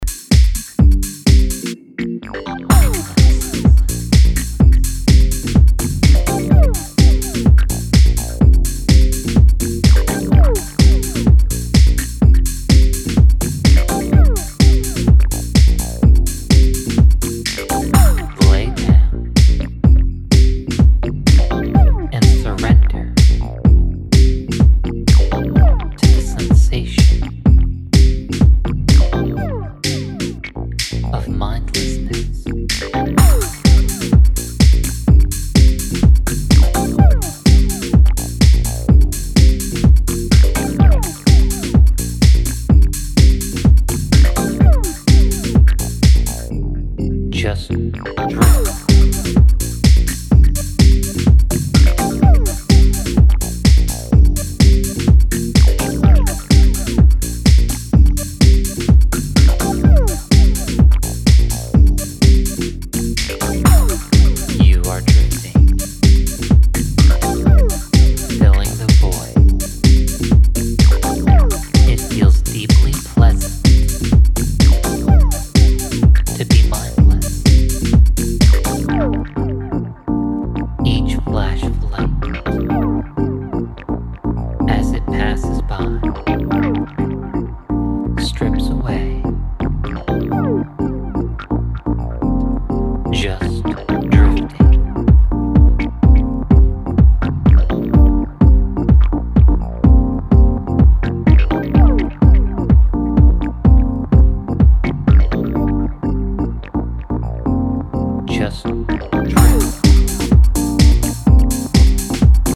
french duo